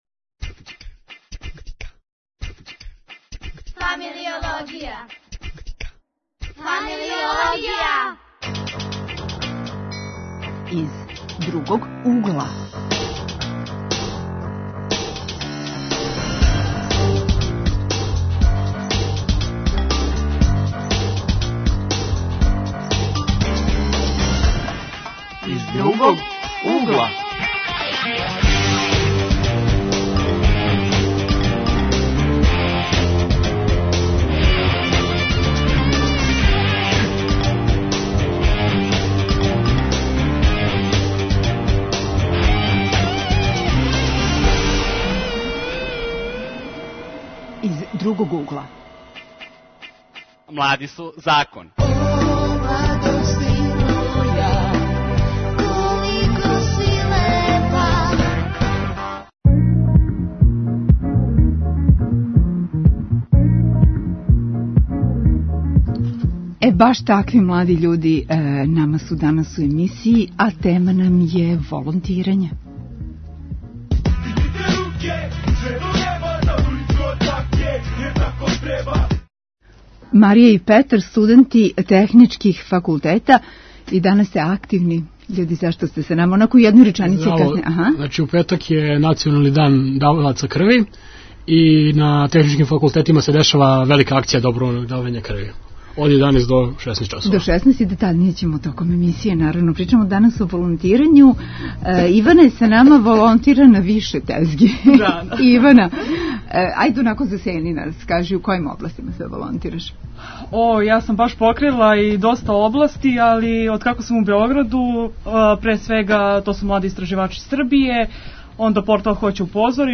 Уз Светски дан Црвеног крста говоримо и о волонтеризму. Гости: студенти-волонтери, активни ових дана.